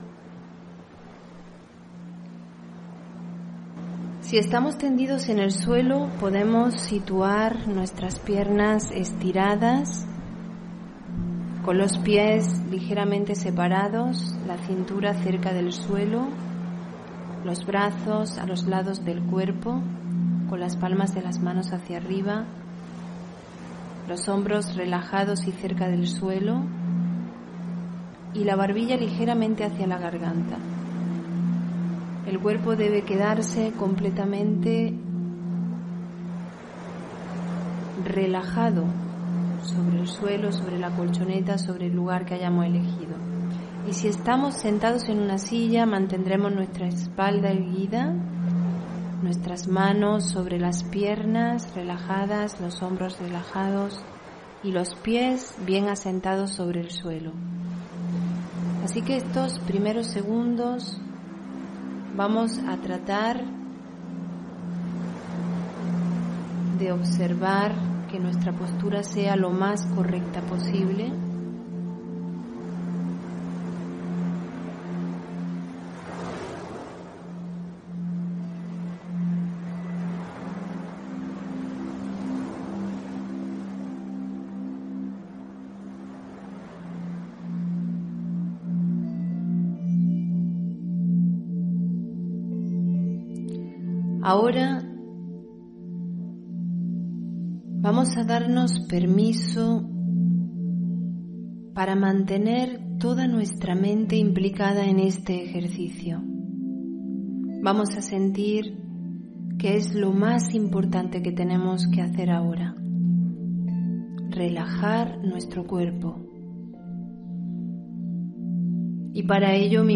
Meditación y conferencia: Relajación completa (7 Junio 2022)